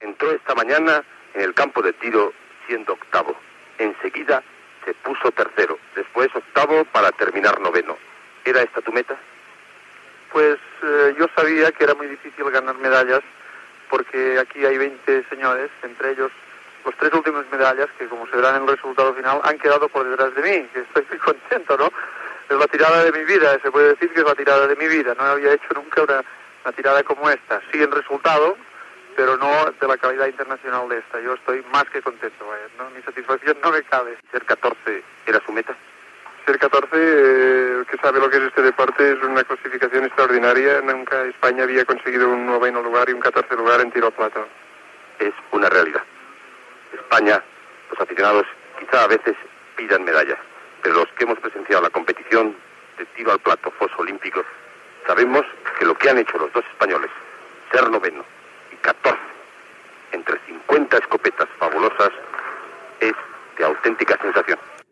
Declaració del tirador al plat
Esportiu